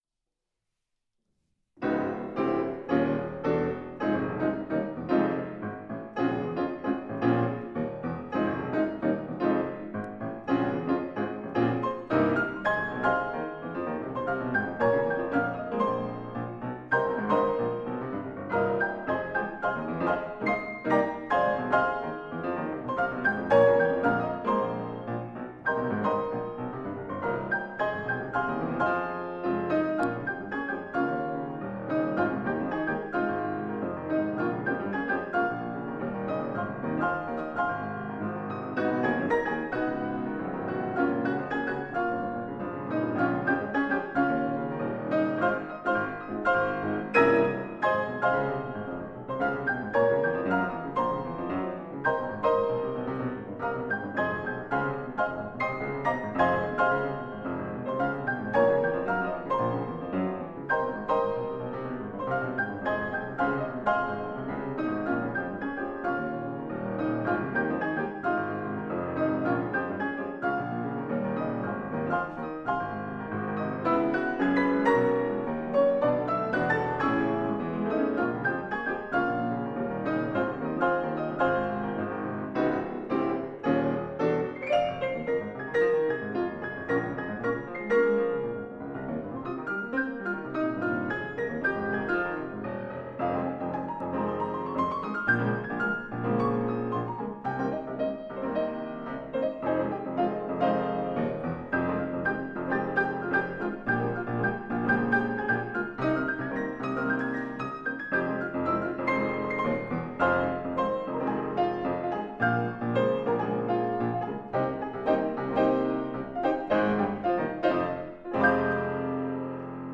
Eigene Arrangements